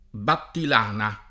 vai all'elenco alfabetico delle voci ingrandisci il carattere 100% rimpicciolisci il carattere stampa invia tramite posta elettronica codividi su Facebook battilano [ battil # no ] o battilana [ battil # na ] s. m.; pl. -ni o -na — sim. i cogn.